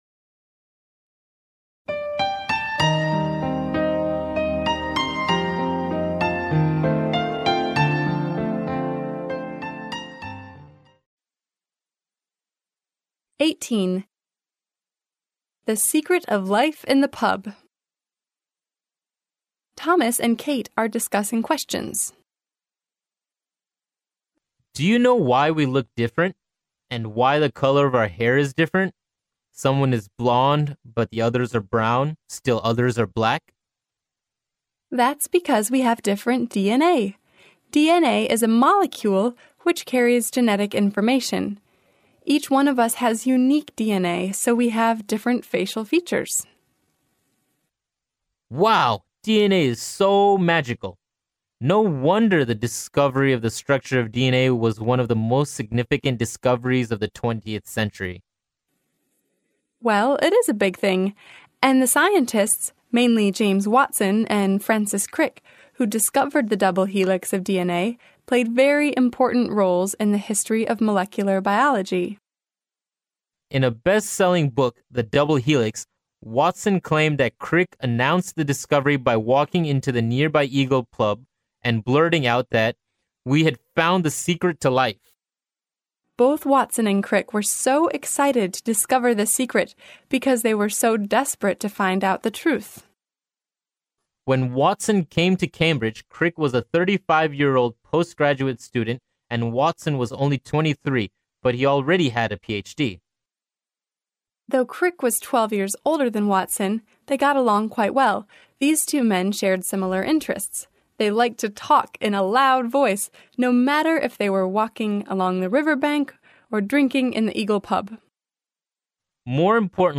剑桥大学校园英语情景对话18：克里克和沃森（mp3+中英）